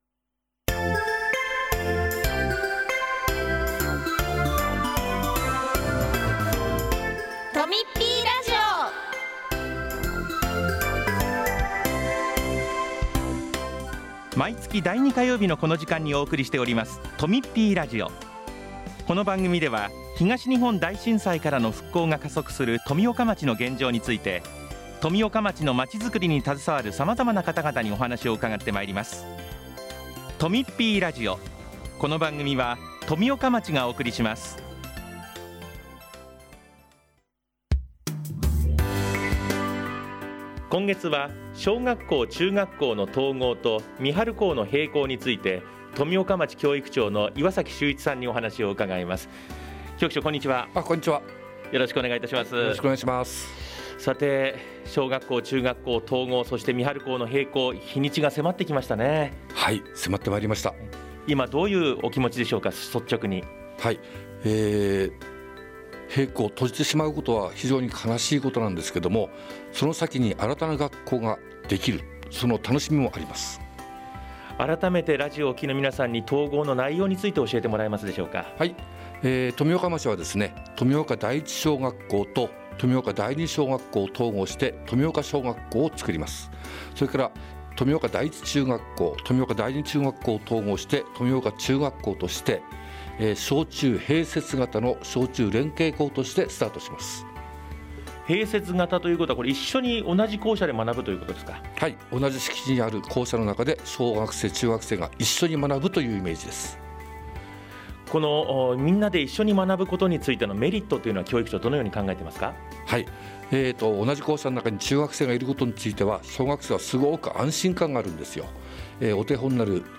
2月8日（火曜日）に放送したとみっぴーラジオをお聴きいただけます。
今月は岩崎秀一教育長が「小学校、中学校の統合と三春校の閉校」をテーマに話をします。